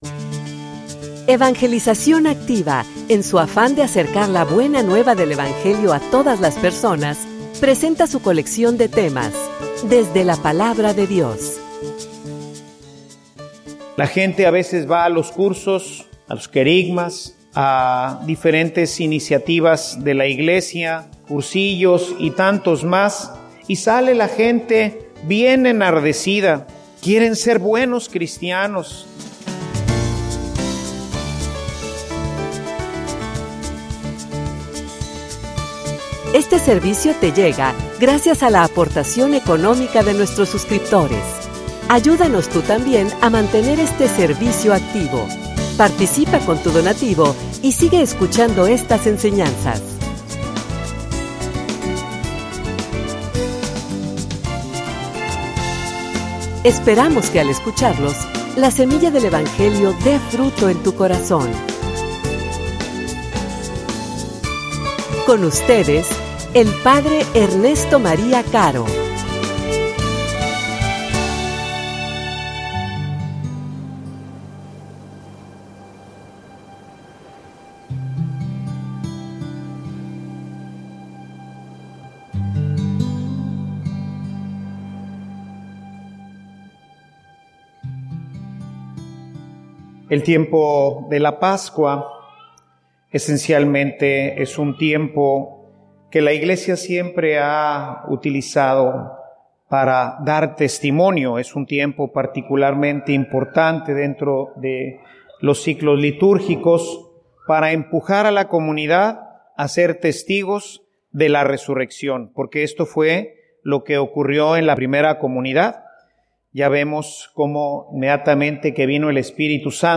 homilia_Lo_importante_de_permanecer.mp3